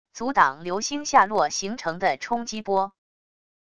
阻挡流星下落形成的冲击波wav音频